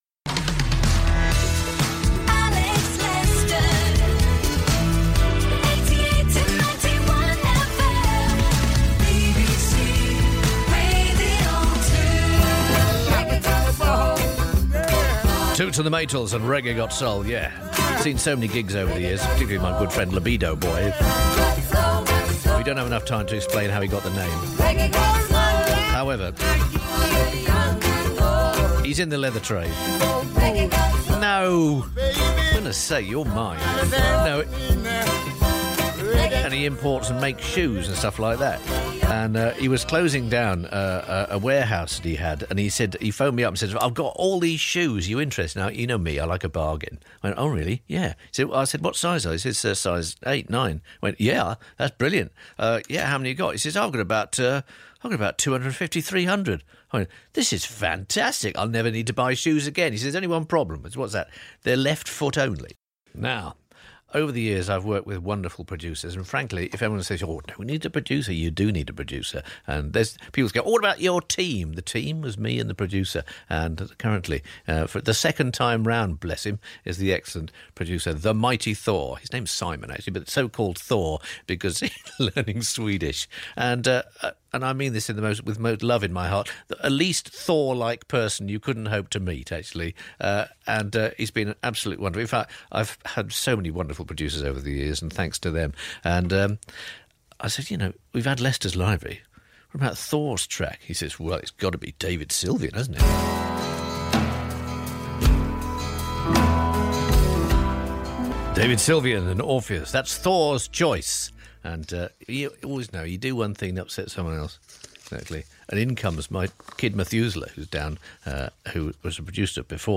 Alex Lester - Final Radio 2 Show
Alex Lester says goodbye to BBC Radio 2 in the wee small hours of Sunday 29 January 2017.